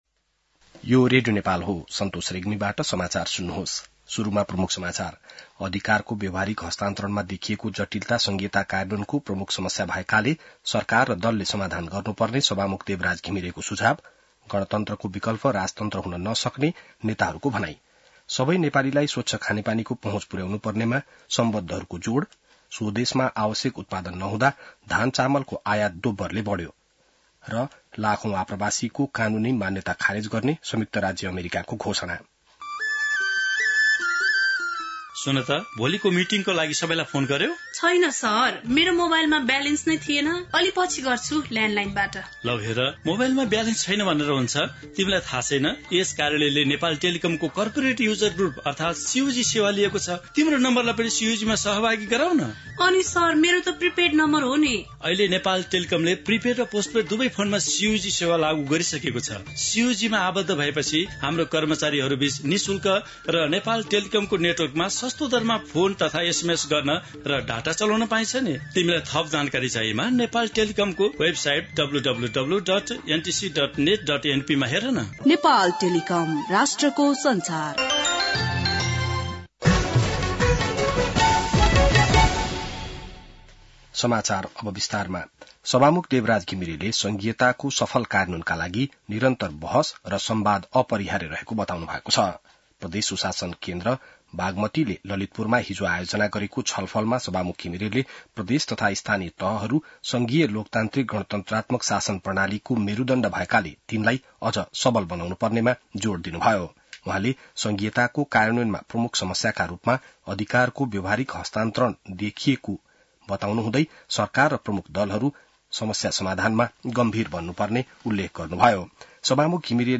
बिहान ७ बजेको नेपाली समाचार : १० चैत , २०८१